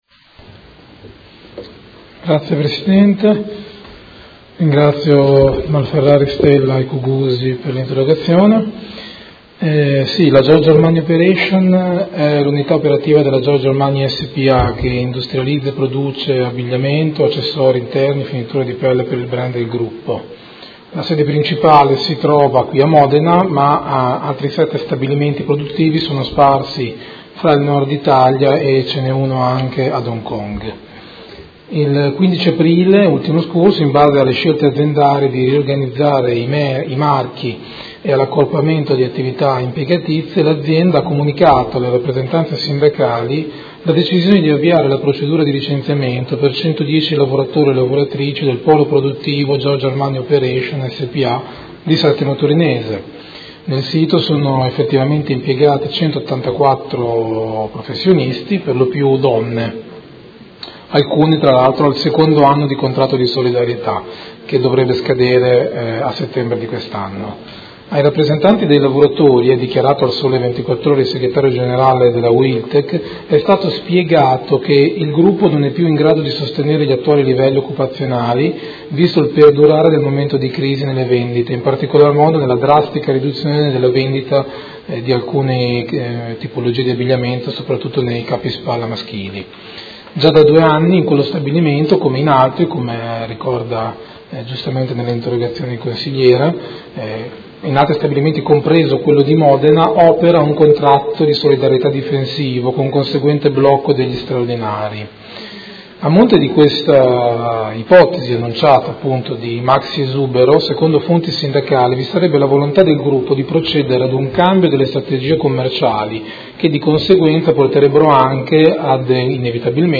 Andrea Bosi — Sito Audio Consiglio Comunale
Seduta del 18/05/2017. Risponde a interrogazione dei Consiglieri Malferrari, Cugusi e Stella (Art.1-MDP) avente per oggetto: Esuberi nel Gruppo Giorgio Armani Operations